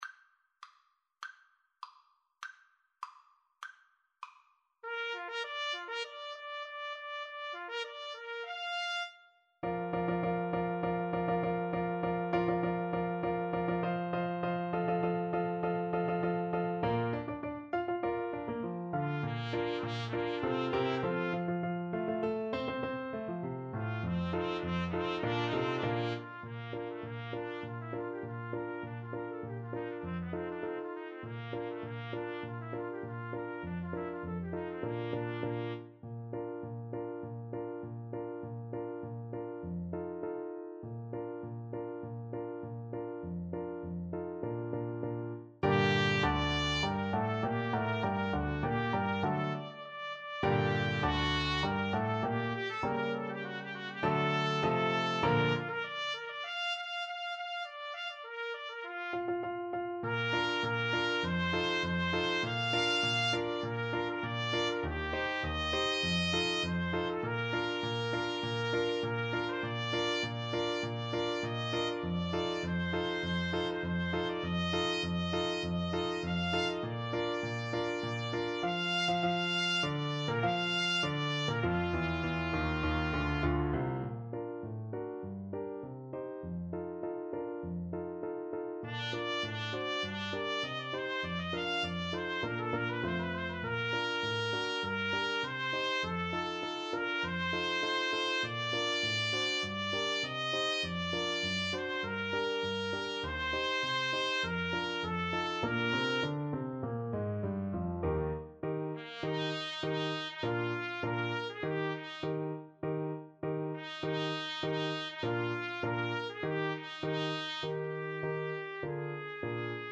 2/4 (View more 2/4 Music)
Bb major (Sounding Pitch) (View more Bb major Music for Trumpet-French Horn Duet )
Classical (View more Classical Trumpet-French Horn Duet Music)